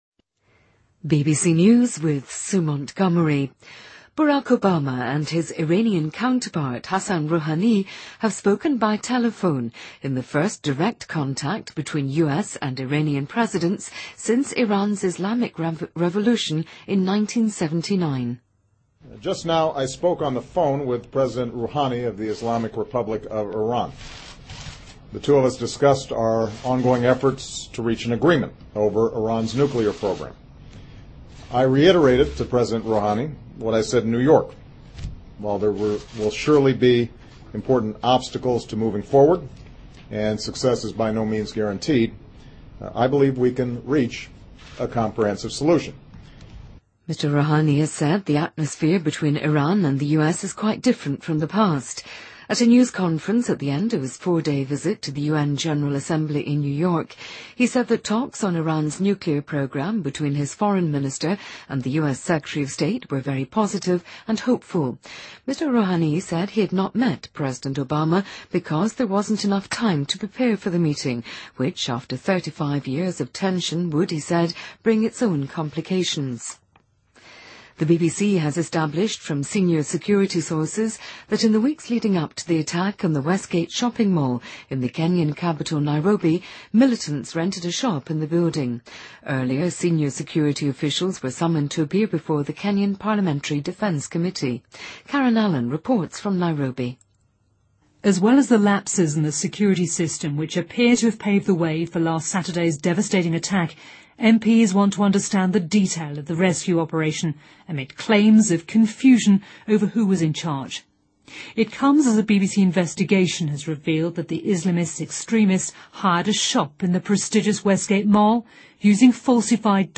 BBC news,巴拉克·奥巴马和伊朗总统哈桑·鲁哈尼进行电话交谈